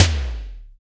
IMPCTTOM LO.wav